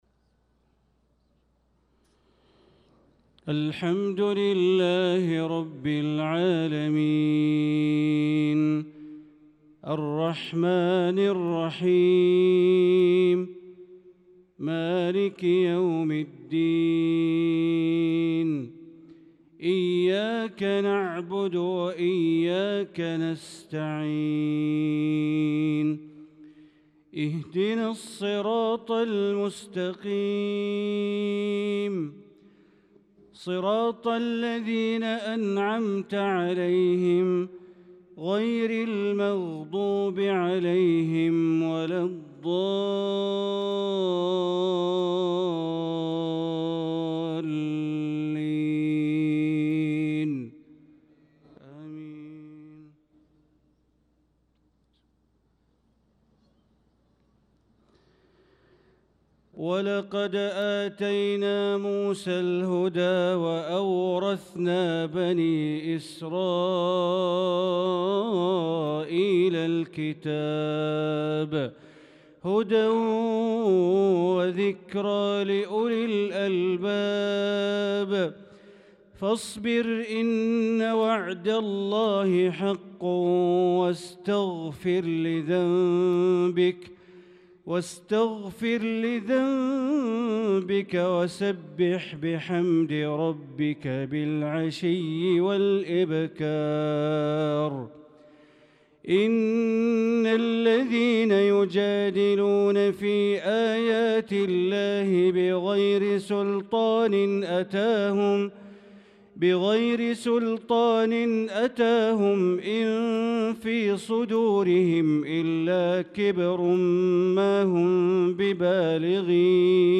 صلاة العشاء للقارئ بندر بليلة 27 شوال 1445 هـ
تِلَاوَات الْحَرَمَيْن .